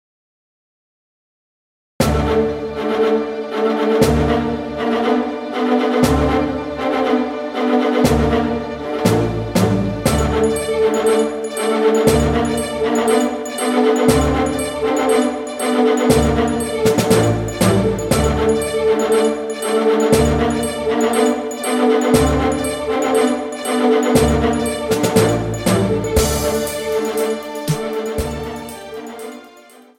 Strings - Backing